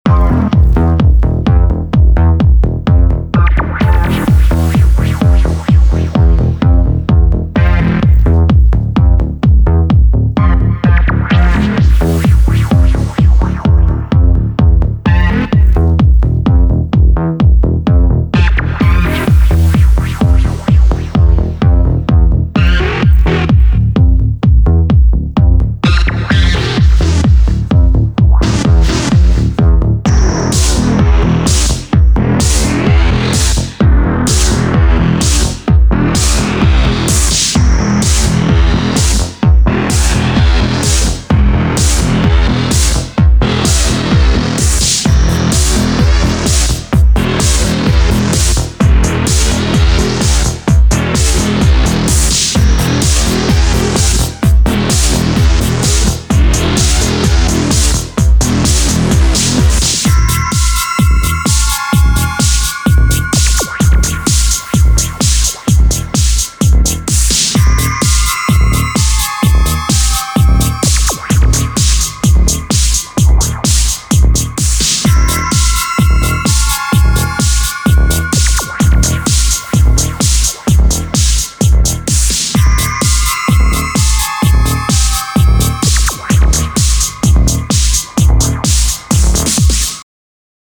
retro-nostalgic melodies and electro-funk sound